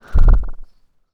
sci-fi_small_spaceship_jet_blast_02.wav